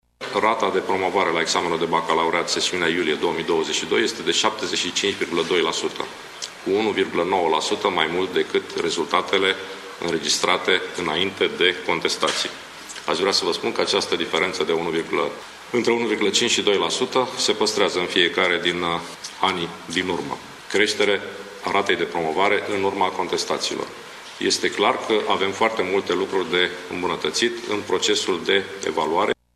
Ministrul Educației, Sorin Câmpeanu, a declarat într-o cinferință de presă că rata de promovare a crescut cu 1,9% după rezolvarea contestațiilor, la 75,2 %.